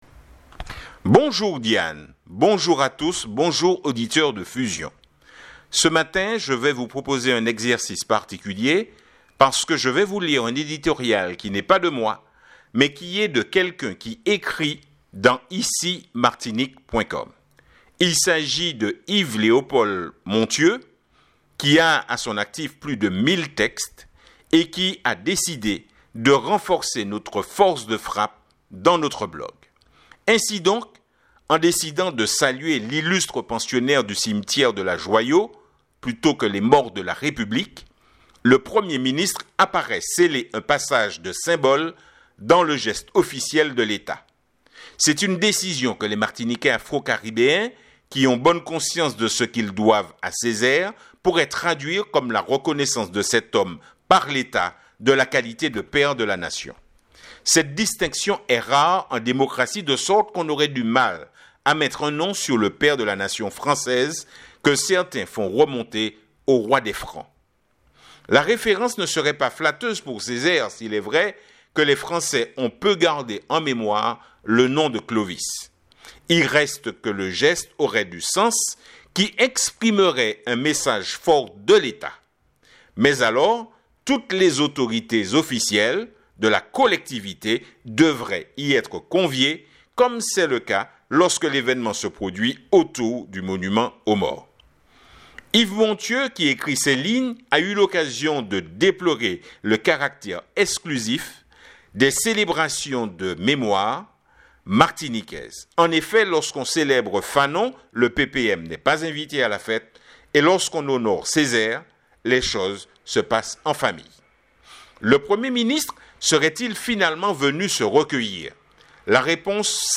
Pour écouter l'éditorial du jour cliquez sur le bouton de démarrage !